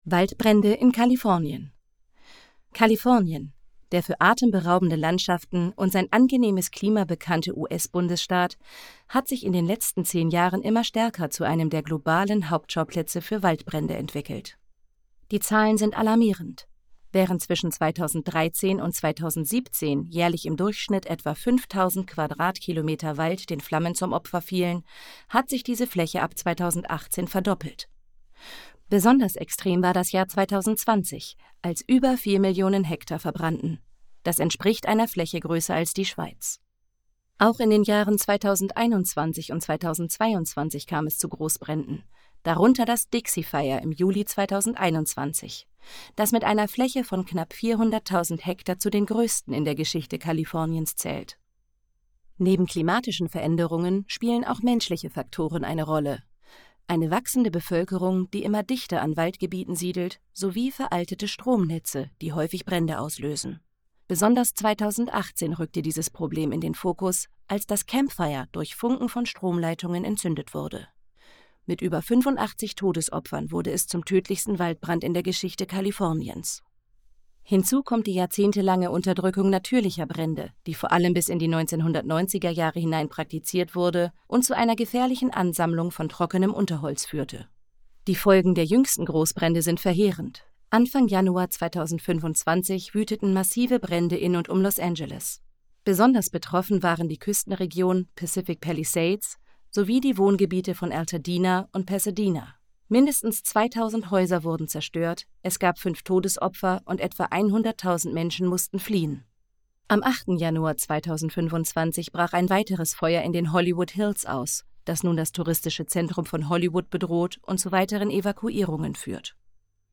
Sprecherin
Feature, Demo